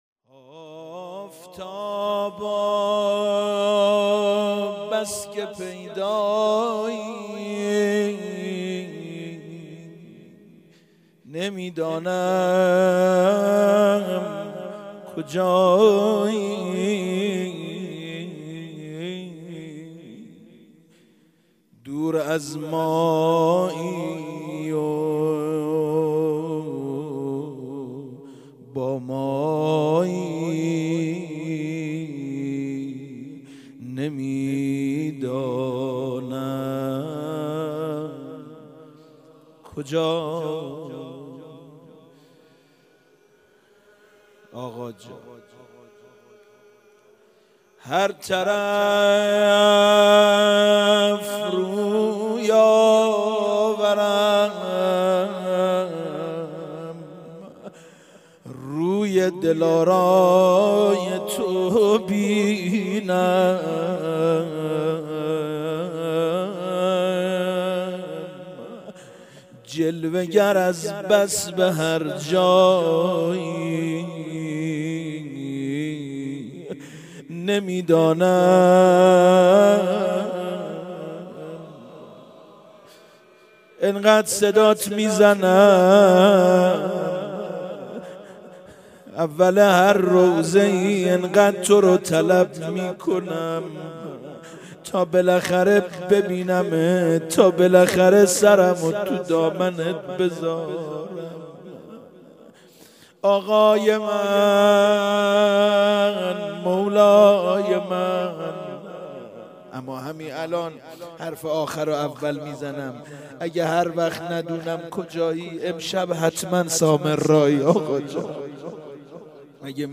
روضه شهادت امام هادی علیه السلام /آفتابا بس که پیدایی نمیدانم کجایی